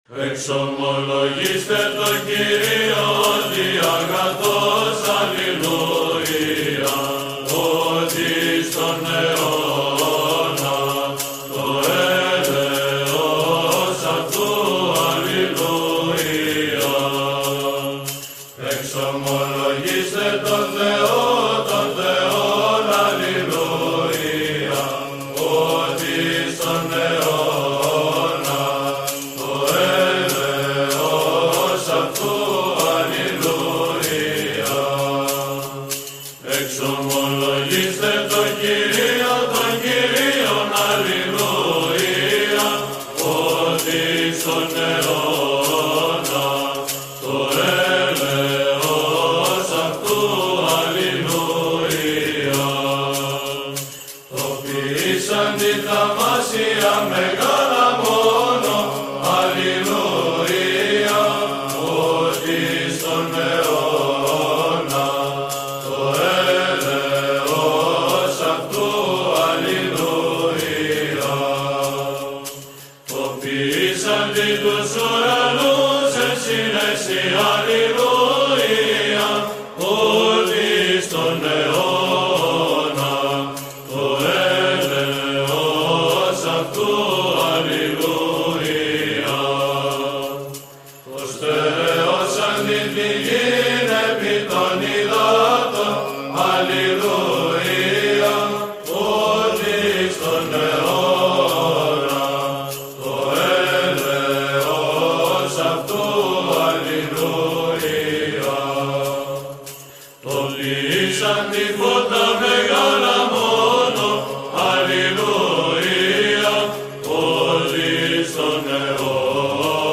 Βίντεο και βυζαντινοί ύμνοι απο το Αγιον Ορος